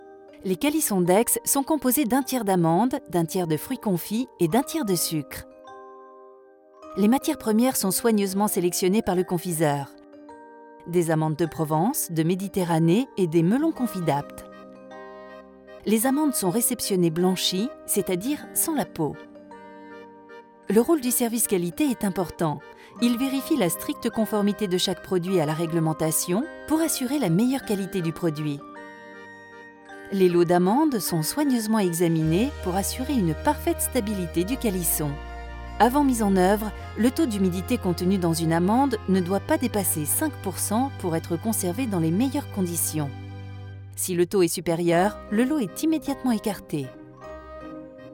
Ma voix peut être chaleureuse, explicative, douce, dynamique, sensuelle ou enjouée.
Sprechprobe: Industrie (Muttersprache):
I have an explaning, soft, dynamic, warm or sensual voice.